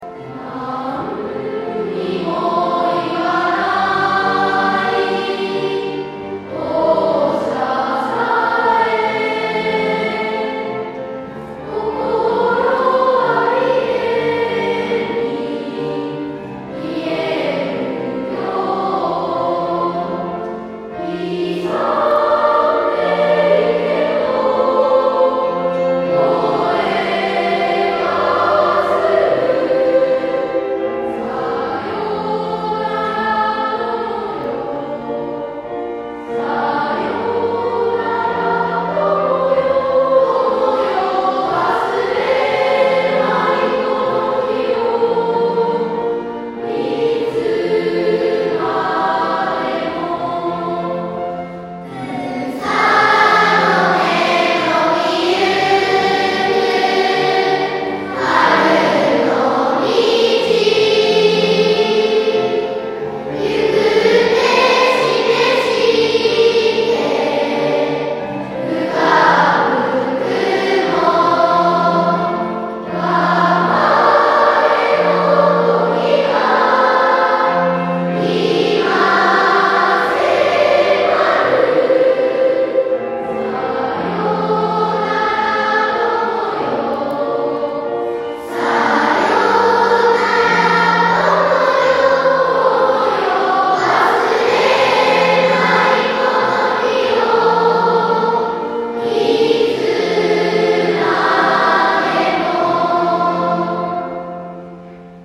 花丸 卒業式その４
別れの言葉です。
大きな声で呼びかけや合唱ができました。